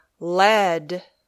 This page: Pronounciation of the phonetic sounds /I/ and /e/
/I/ sound /e/ sound